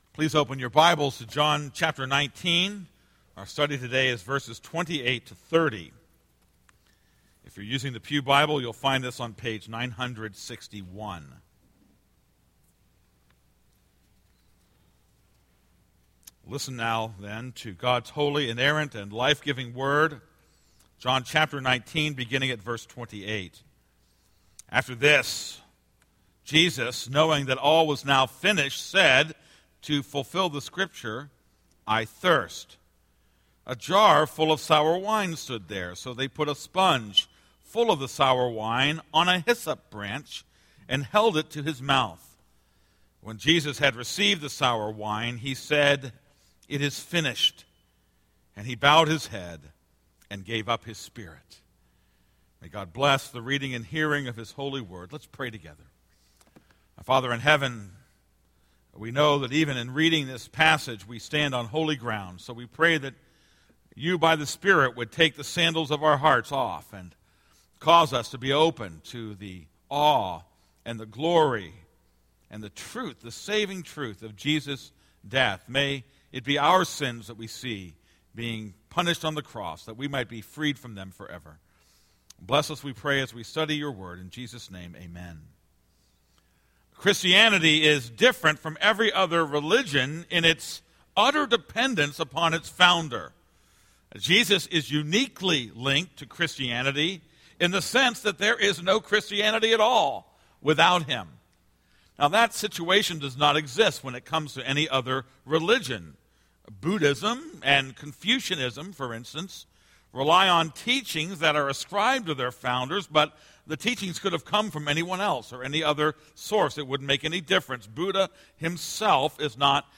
This is a sermon on John 19:28-30.